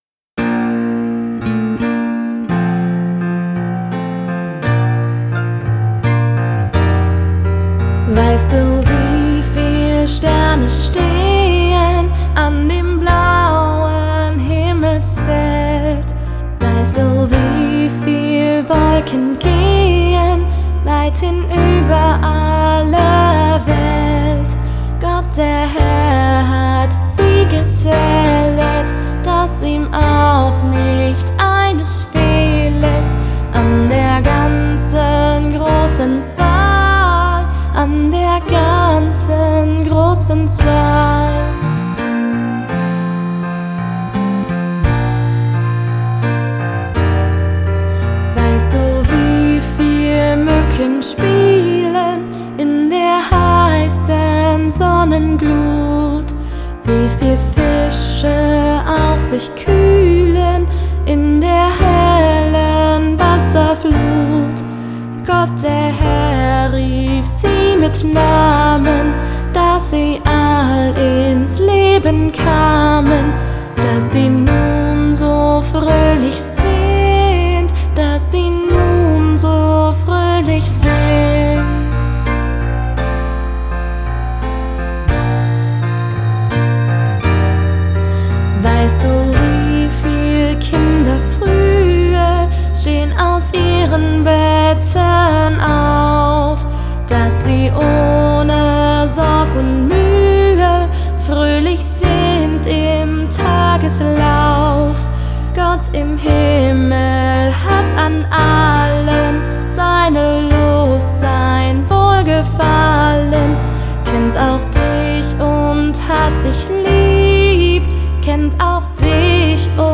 Schlaflieder